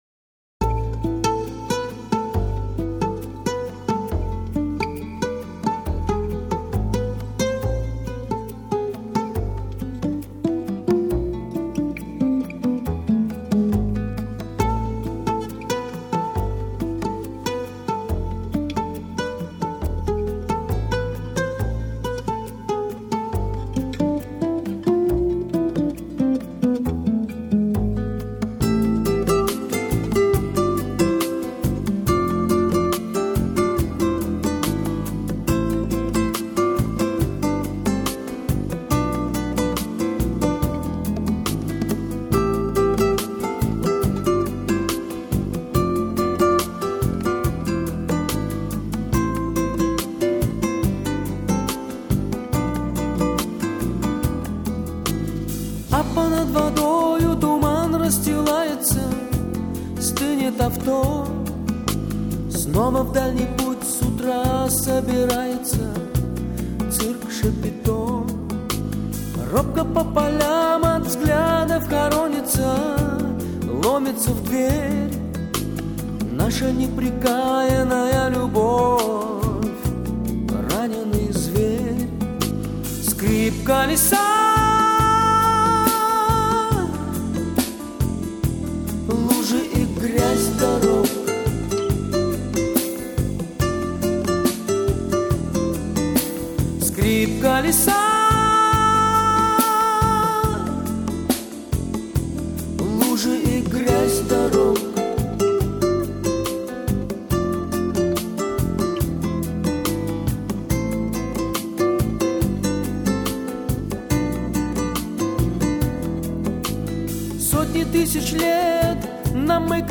скрип колеса
skrip-kolesa.mp3